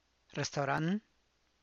rrestauran[rrestaura’ann]